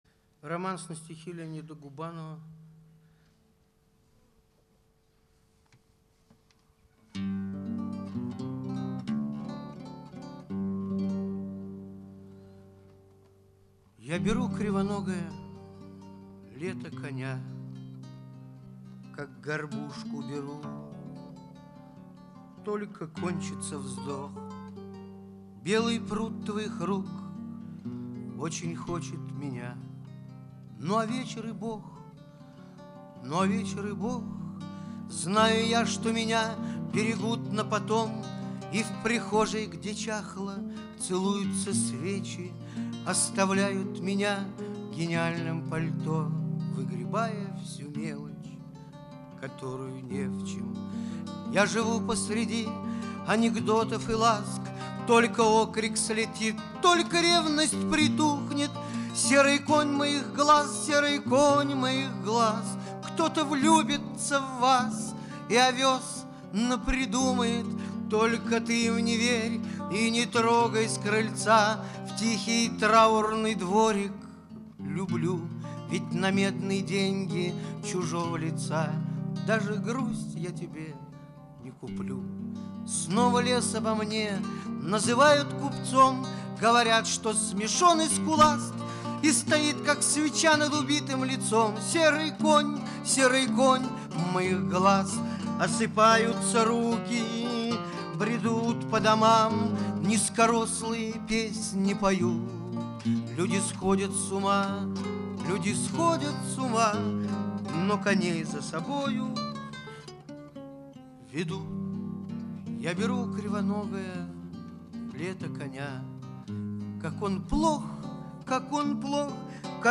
Концерт в ДК Прожектор 1980г